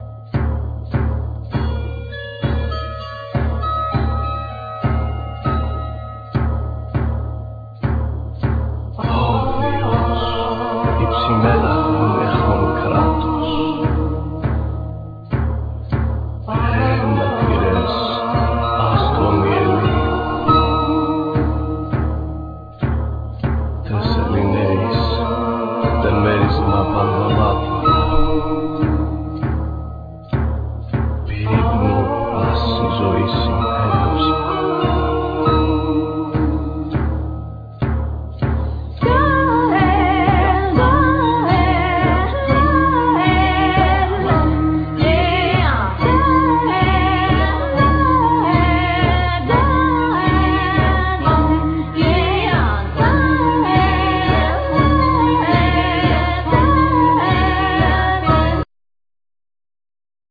Electronics,Sampler,Voices
Violin
Daouli